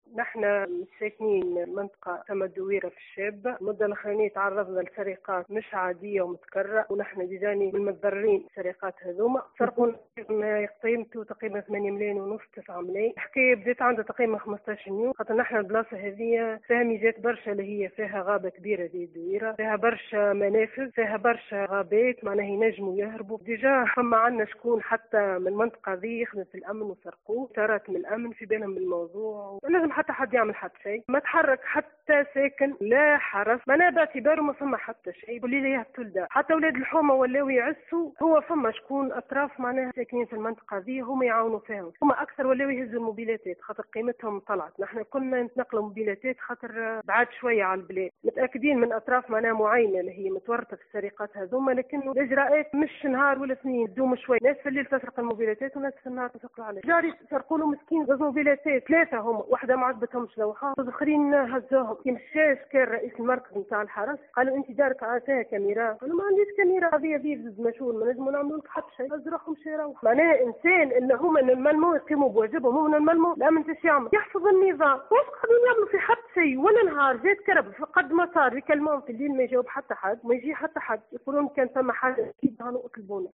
وجهت إحدى متساكنات منطقة الدويرة من معتمدية الشابة التابعة لولاية المهدية، نداء استغاثة، في تصريح ل “ام اف ام”، بسبب كثرة السرقات يوميا بمنطقتهم دون تدخل أمني، حيث تعرضت مؤخرا إلى سرقة بقيمة 9 الاف دينار، حسب قولها.